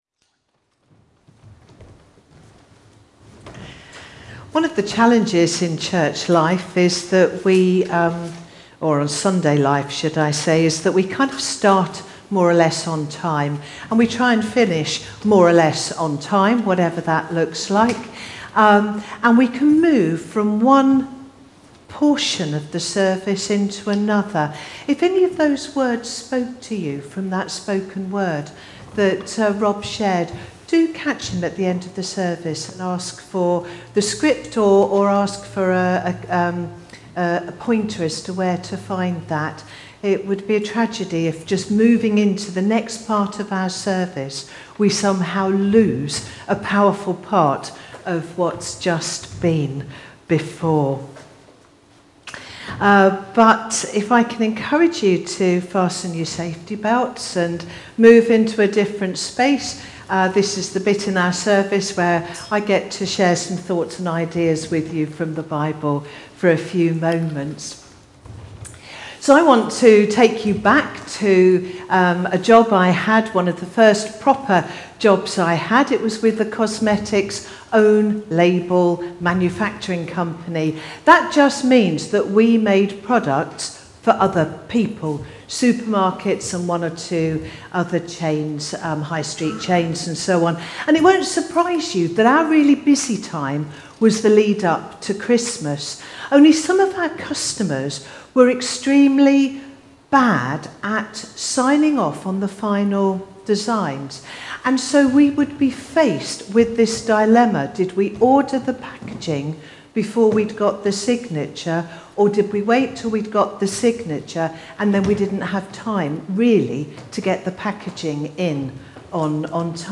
12th-October-On-the-Frontline-Whatever-You-Do-Sermon.mp3